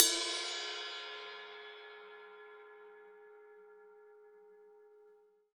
Index of /90_sSampleCDs/USB Soundscan vol.10 - Drums Acoustic [AKAI] 1CD/Partition C/03-GATEKIT 3